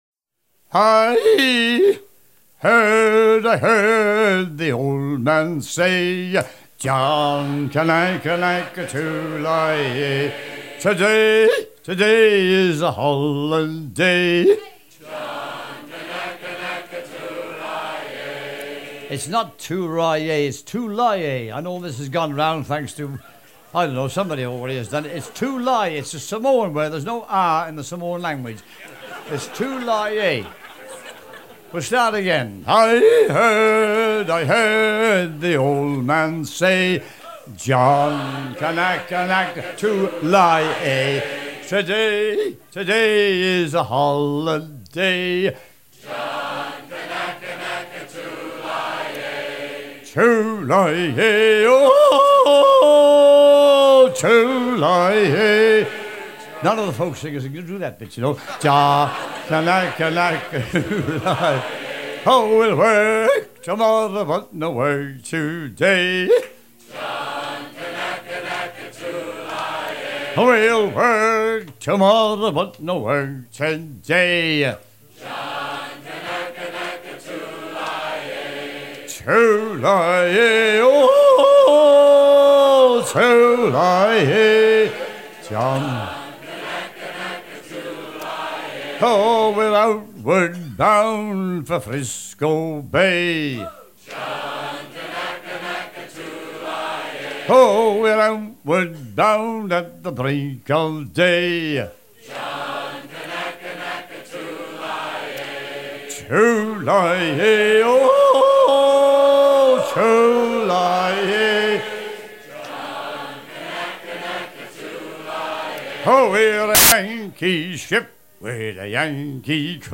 enregistré au musée Mystic Seaport au USA le 11 juin 1988
à hisser main sur main
Chansons maritimes